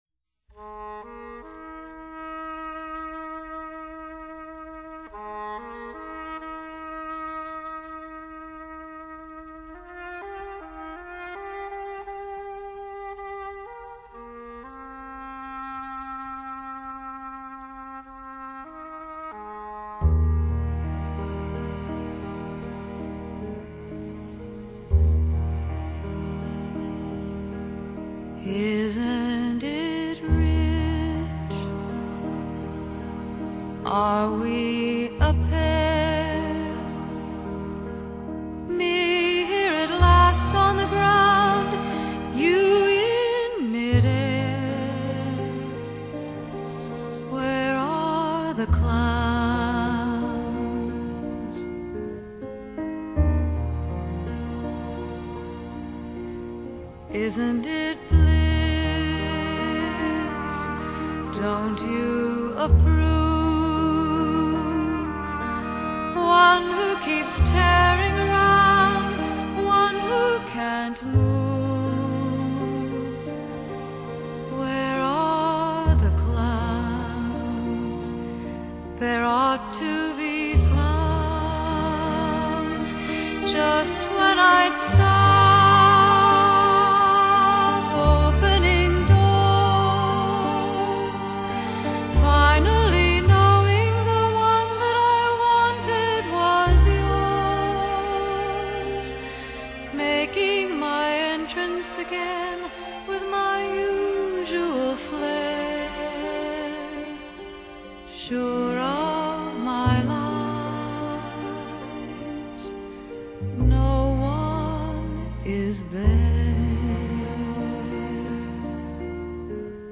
Folk/Folk-Rock | EAC Rip | Flac(Image) + Cue + Log / BaiDu
她的嗓音清亮透明，充滿女性純真的柔美感性，每一首歌經過她的詮釋，都變得具有淨化心靈的神奇效果。